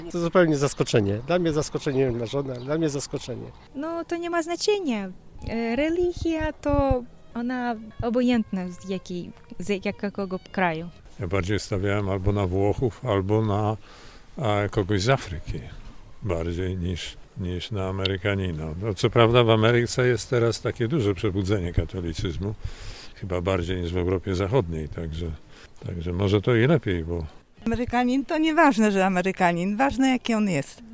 Zapytaliśmy też czy pochodzenie papieża zaskoczyło mieszkańców Wrocławia.